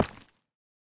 Minecraft Version Minecraft Version latest Latest Release | Latest Snapshot latest / assets / minecraft / sounds / block / deepslate / break2.ogg Compare With Compare With Latest Release | Latest Snapshot